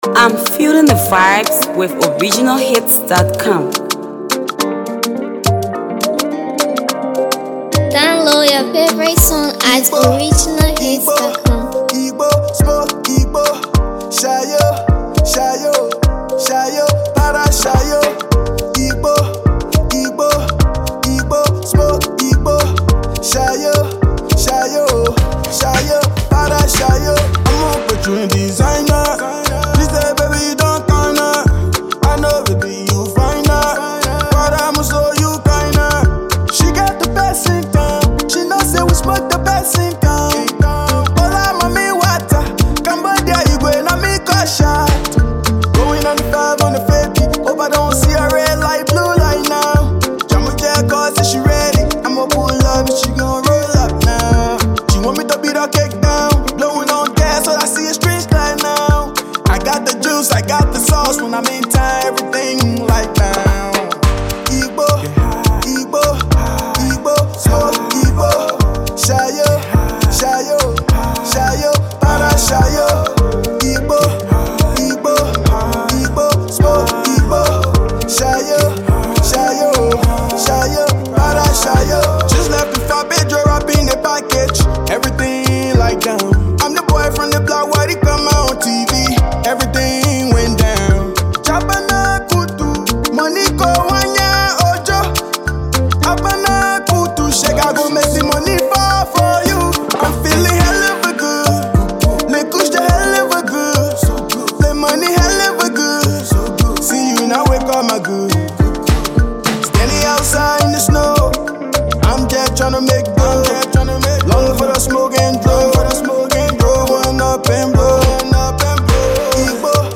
Talented Liberian uprising artist